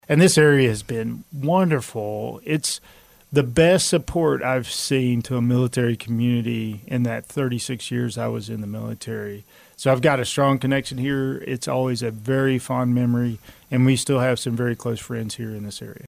The Manhattan Area Chamber of Commerce held its monthly military relations luncheon on Friday with its featured speaker being retired Sergeant Major of the Army Michael Tony Grinston who was recently appointed the CEO of Army Emergency Reserve.